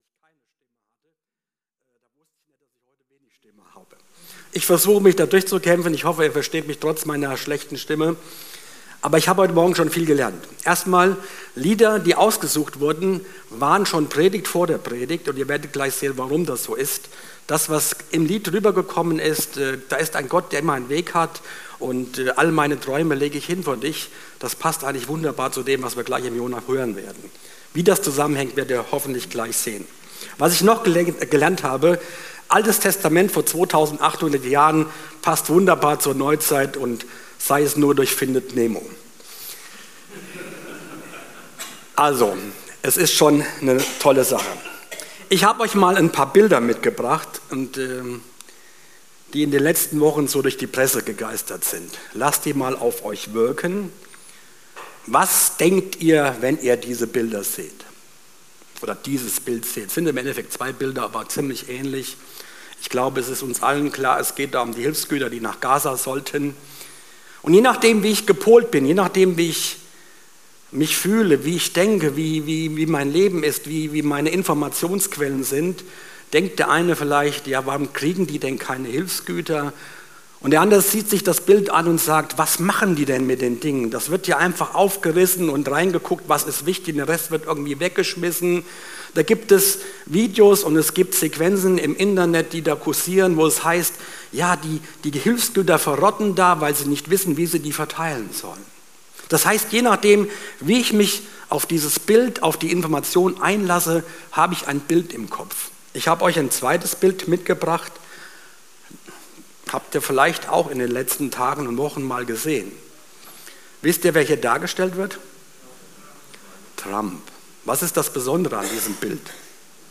31.08.2025 Wortbetrahtung ~ Predigten - FeG Steinbach Podcast